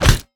FleshWeaponHit1.ogg